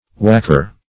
Whacker \Whack"er\, n.